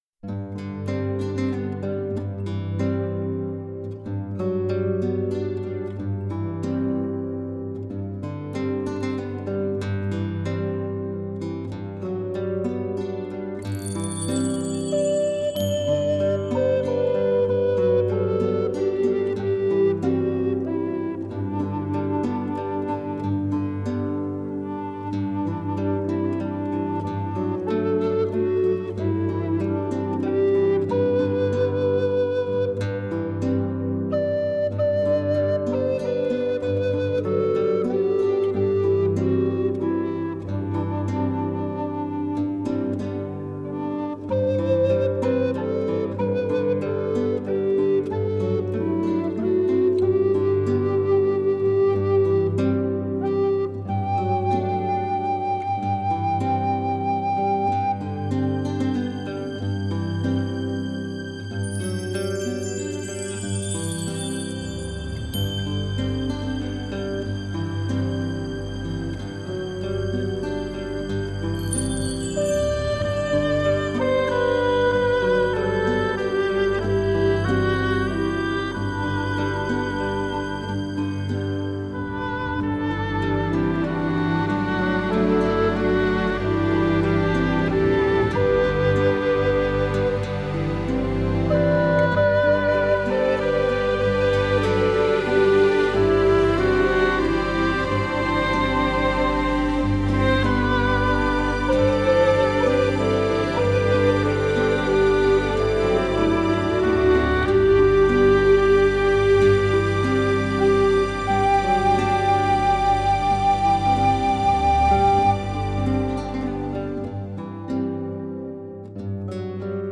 Стиль: NewAge, Christmas